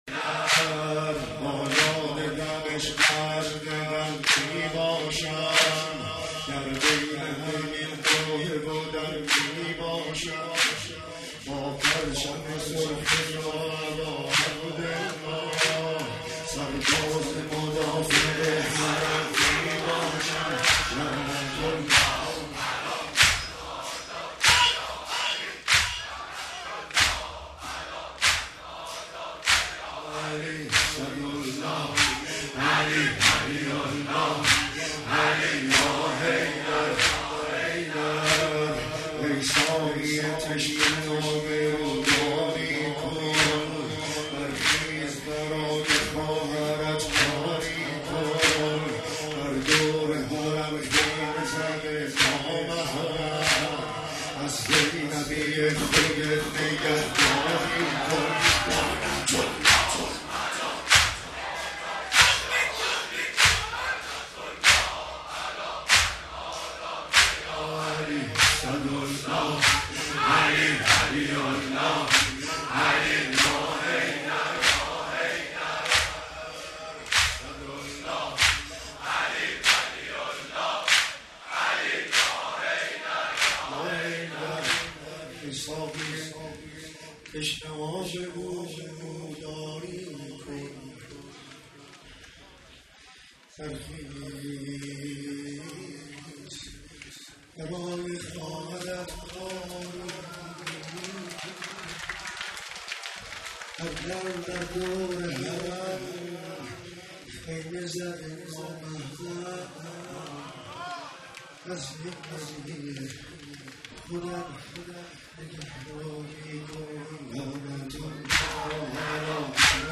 مداحی